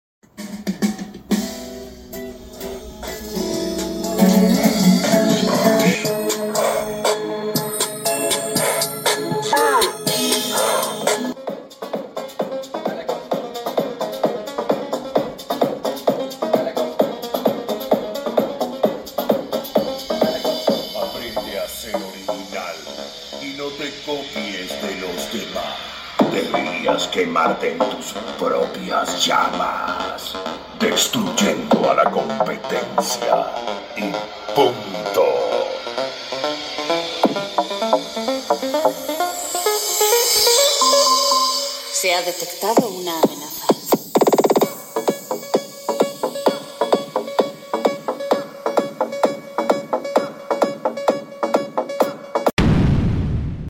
Aerohorn y cajita mañosa por sound effects free download